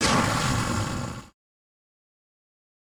elder_hit3.ogg